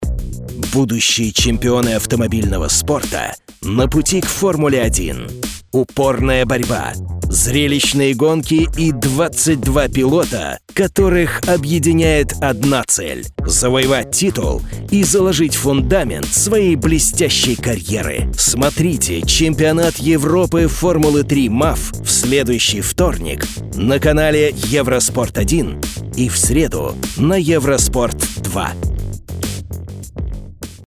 Russian voice over. recording promo Eurosport